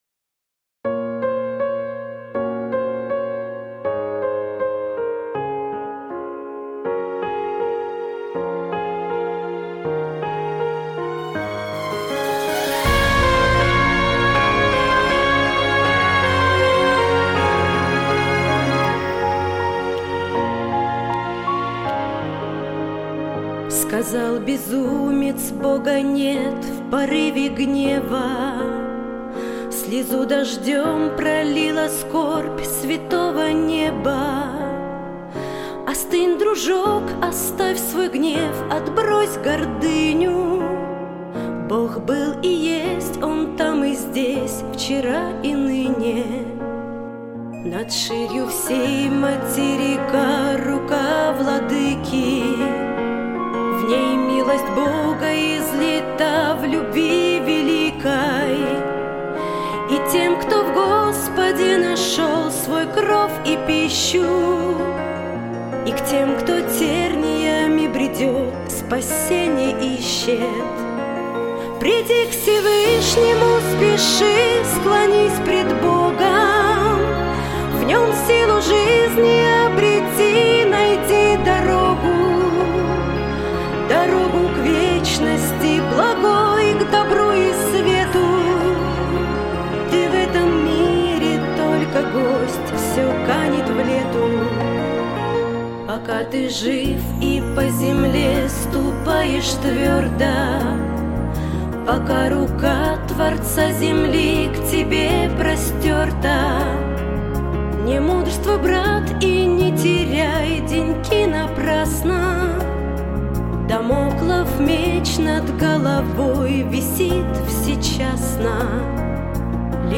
песня
894 просмотра 1681 прослушиваний 222 скачивания BPM: 75